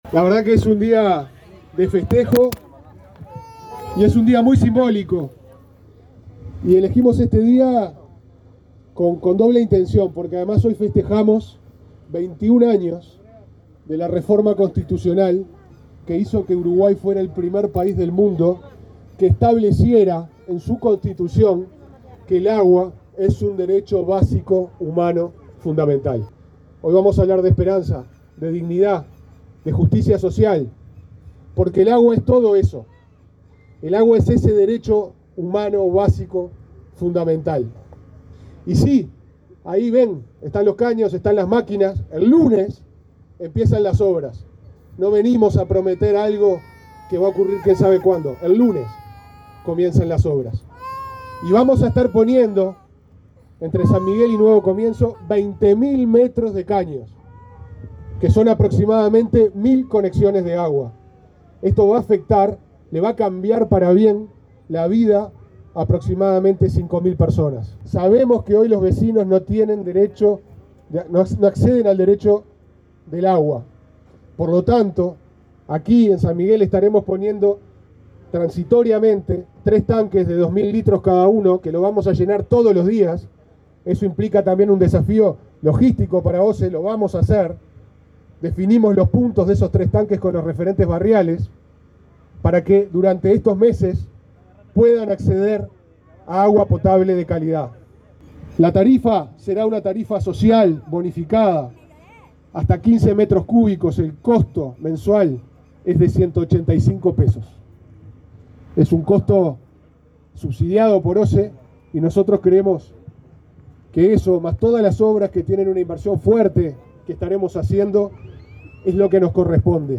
Palabras del presidente de OSE, Pablo Ferreri
Palabras del presidente de OSE, Pablo Ferreri 31/10/2025 Compartir Facebook X Copiar enlace WhatsApp LinkedIn Durante el lanzamiento de las obras de conexión a la red de agua potable en los barrios Nuevo Comienzo y San Miguel, de Montevideo, se expresó el presidente de OSE, Pablo Ferreri.